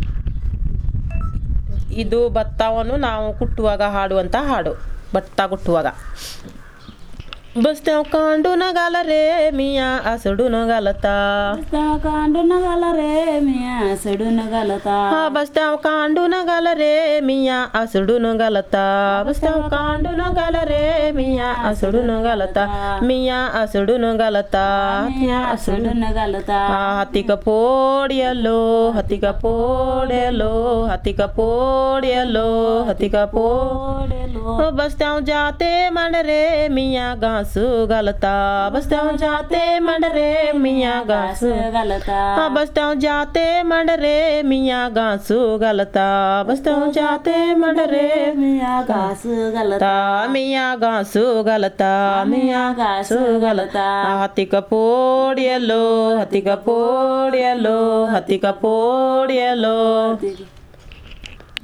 Performance of traditional folk song
usually sung while pounding paddy.